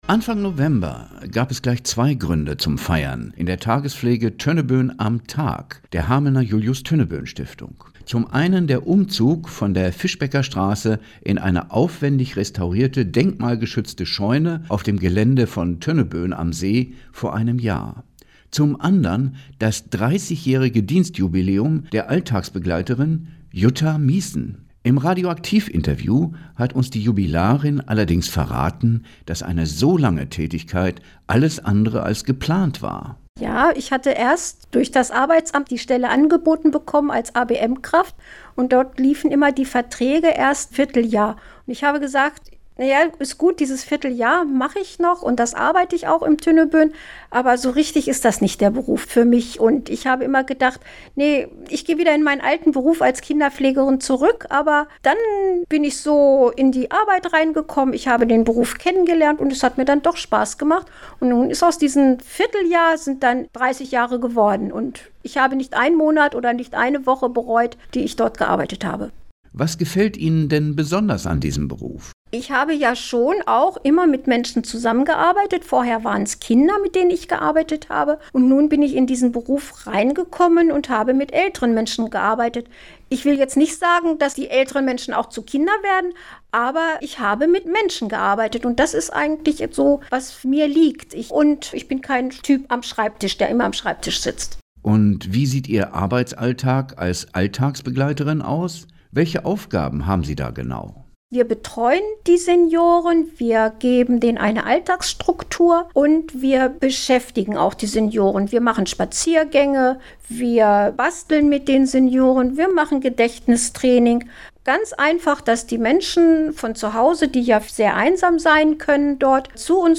Jubiläumsbeitrag Tagespflege Das Radiointerview von Radioaktiv als mp3